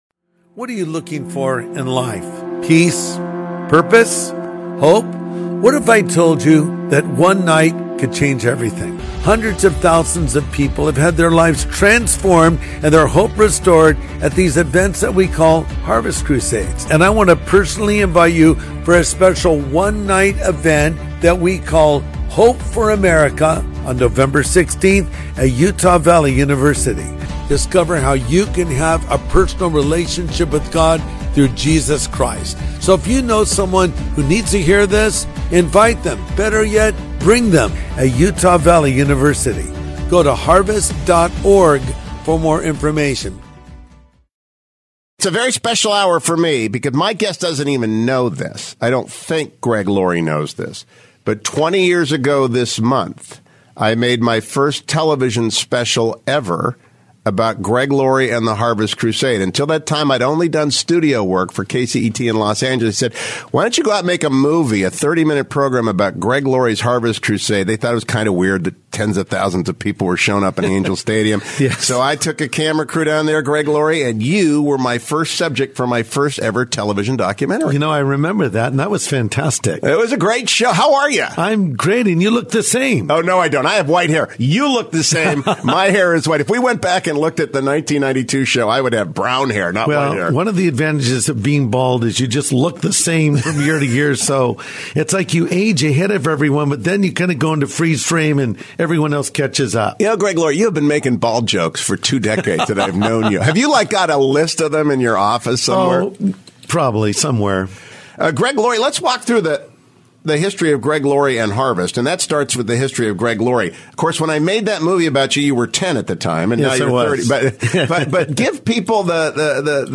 In this interview from the archives, Pastor Greg Laurie sits down with Hugh Hewitt to open up about his upbringing, running into the gospel at 17 on a high school campus, and becoming a successful evangelist, despite being the last person many would have expected.